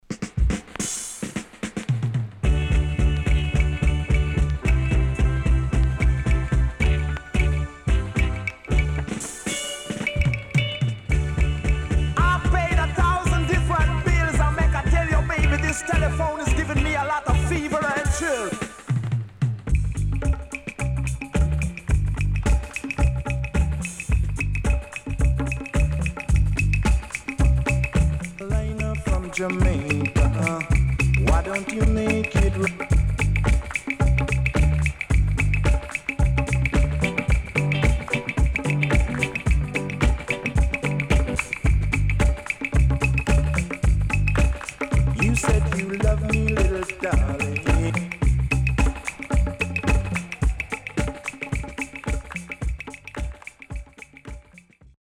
HOME > Back Order [VINTAGE 7inch]  >  INST 70's
SIDE A:盤質は良好です。